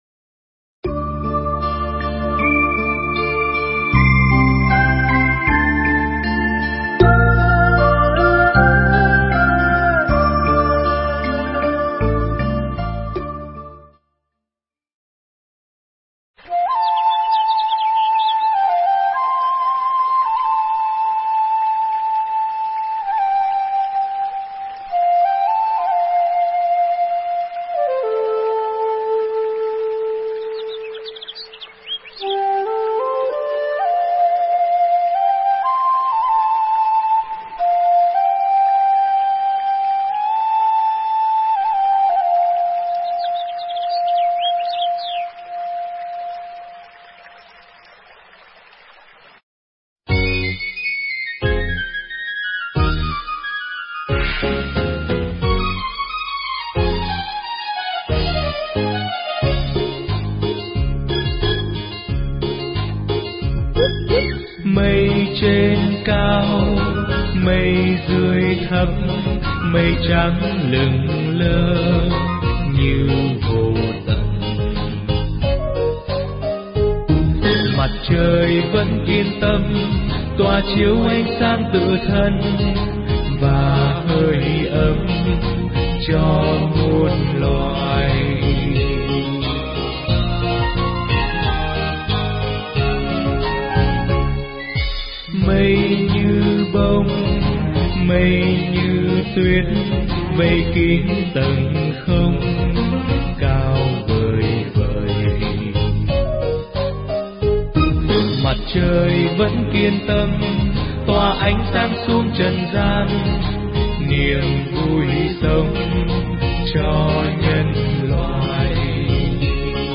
Nghe Mp3 thuyết pháp Thanh Tẩy Thân Tâm
Tải mp3 pháp thoại Thanh Tẩy Thân Tâm